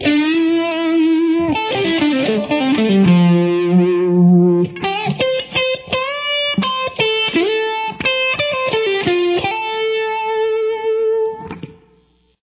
Solo
1st lick